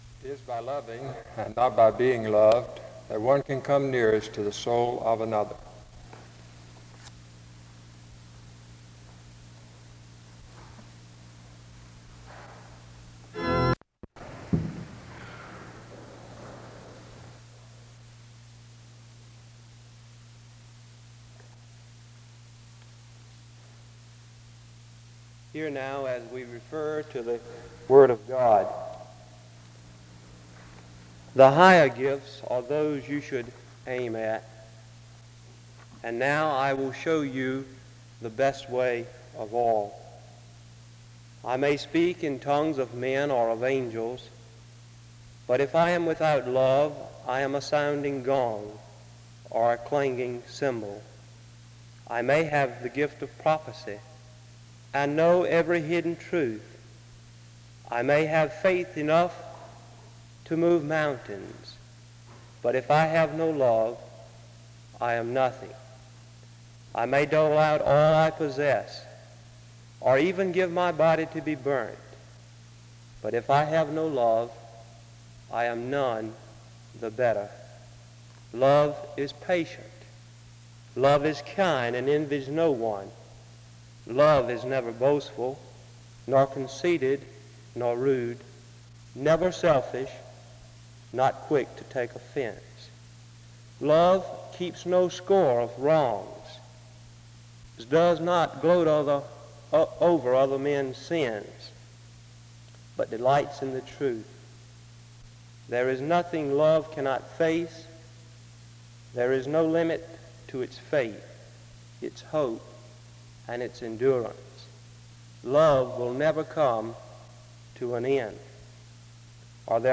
The service starts with an opening scripture reading from 0:25-3:07. A prayer is offered from 3:14-4:09.
A responsive reading takes place from 9:19-11:58. A closing prayer is offered from 12:19-12:40.
In Collection: SEBTS Chapel and Special Event Recordings SEBTS Chapel and Special Event Recordings